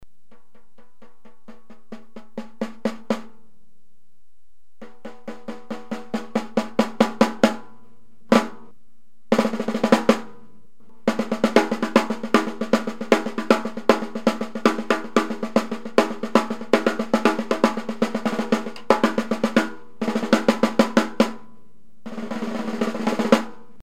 Hello, I'm a french drummer and I've got a 14*4 Ludwig Universal snare drum with those old snare wires (a bit like guitar strings).
The problem is that it is very difficult to tune them high, they are always loose and it makes a buzz sound everytime.
By the way here is how it sounded when it was tuned high.
The heads are not the original ones, it is a natural calf skin on the batter side and a clear ambassador snare on the snare side.